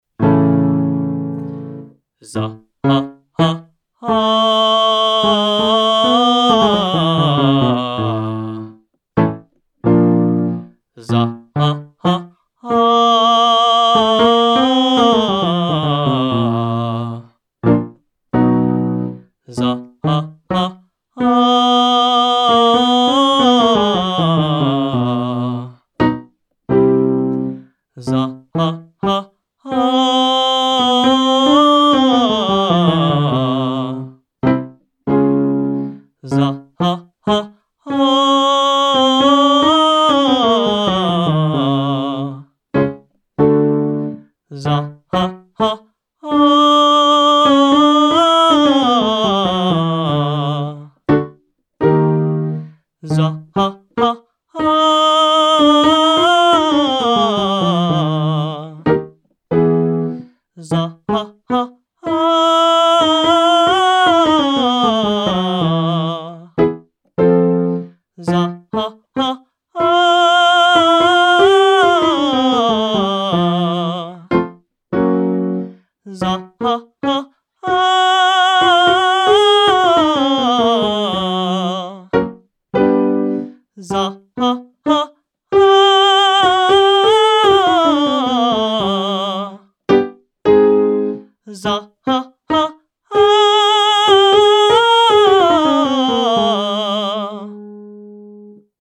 First we arpeggiate a chord upward.
Then immediately engage your support again to bump up to the highest note with a healthy belt.